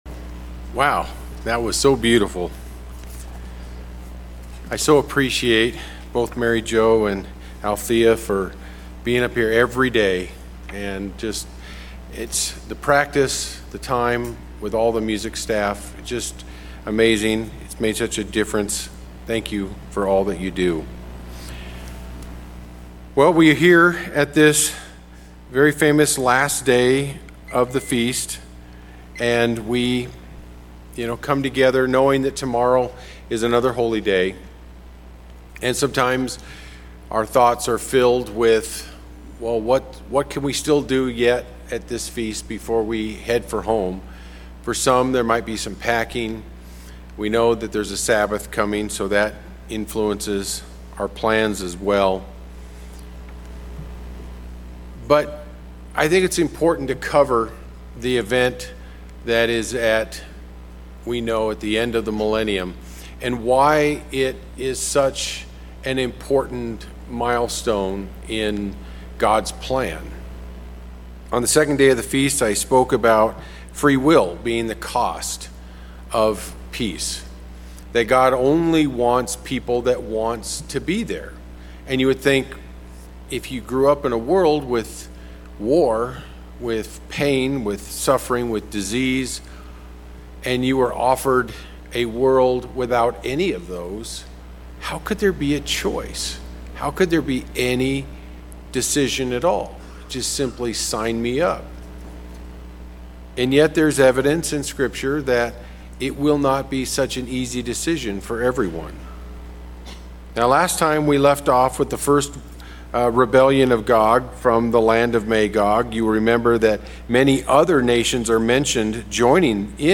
Sermons
Given in Klamath Falls, Oregon